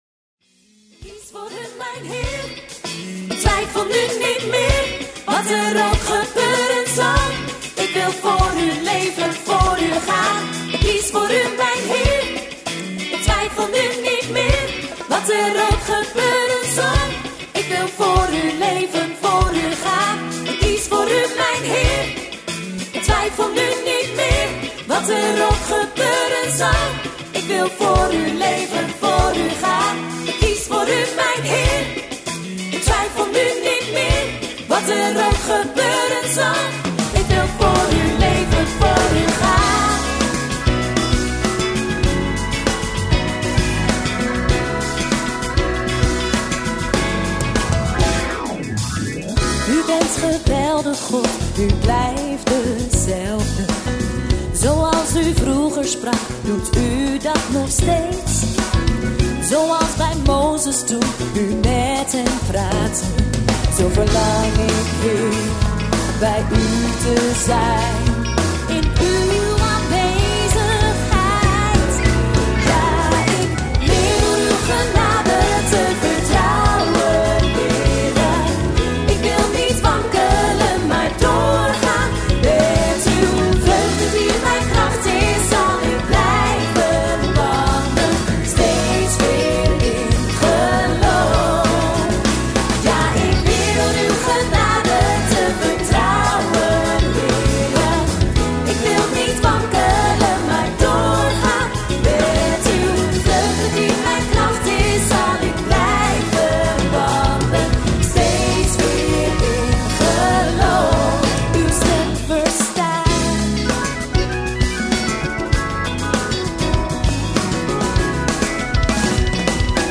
Genre Live Worship